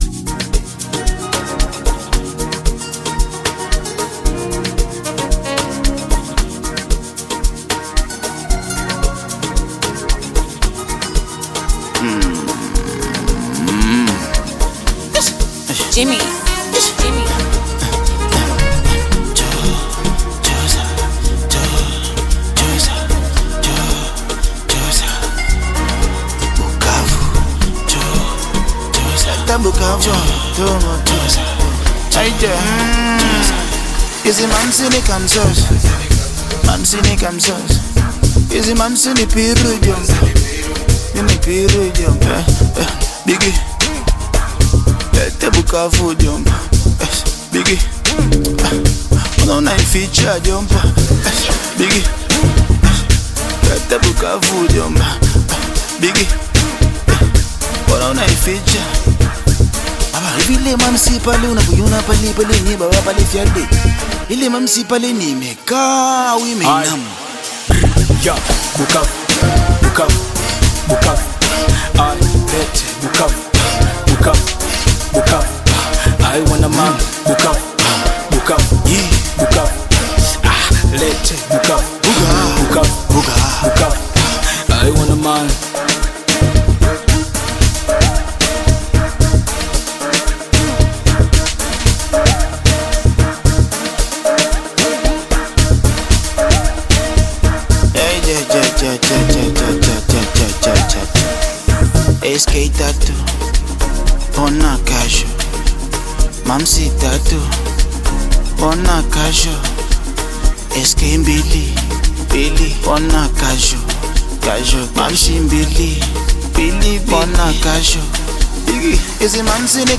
a Tanzaian/East African Amapiano fusion collaboration
amapiano